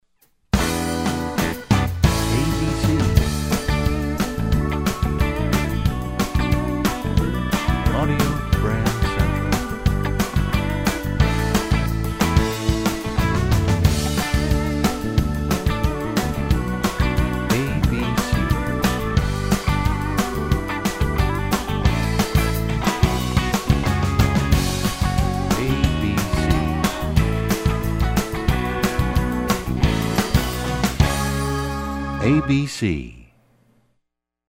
MCM Category: Radio Jingles